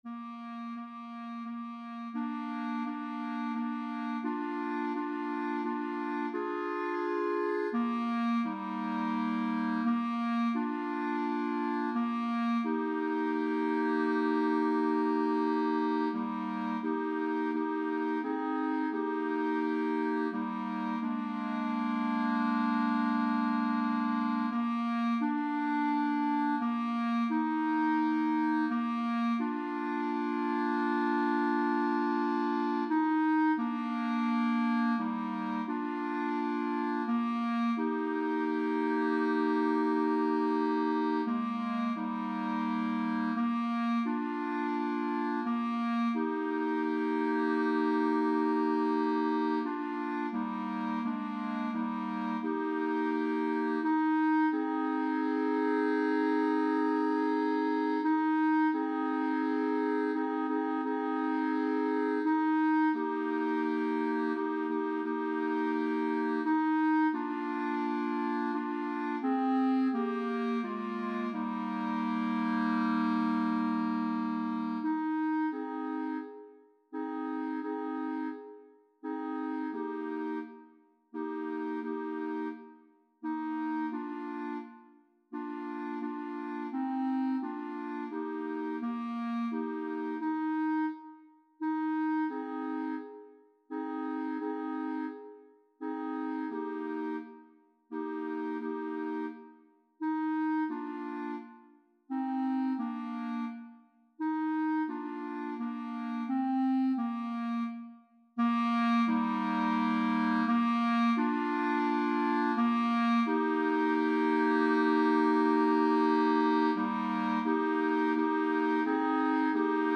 A couple of hours ago, a friend asked me to write a trio for three beginning clarinets.
WALTZ MUSIC